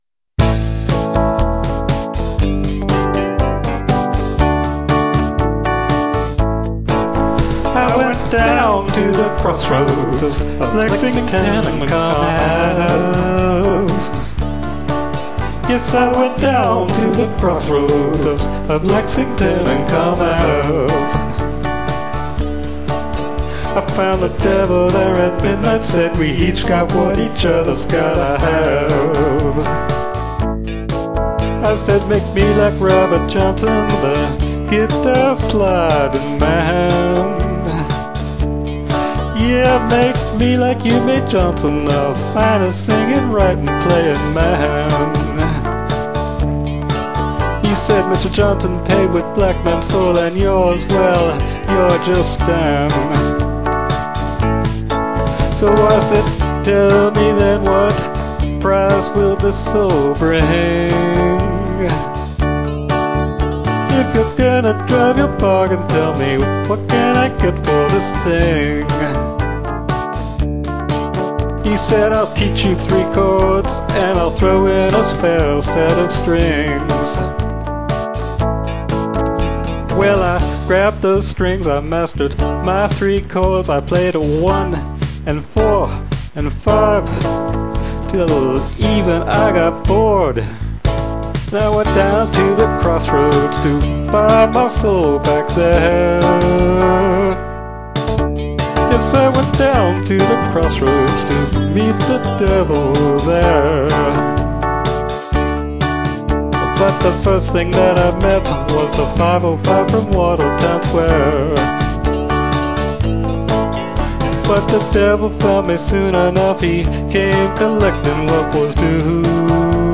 blues, male voice
12-BAR BLUES IN D EXCEPT AS NOTED